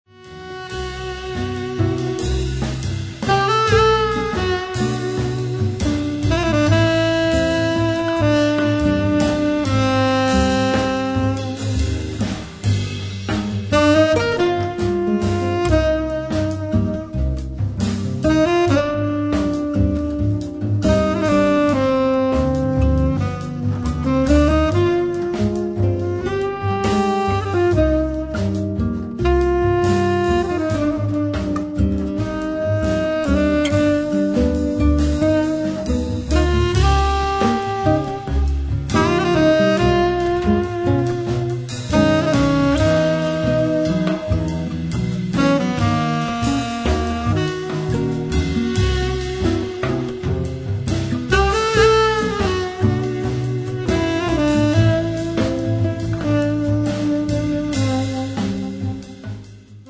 Sax tenore e soprano, clarinetto
Chitarra classica
Contrabbasso
Batteria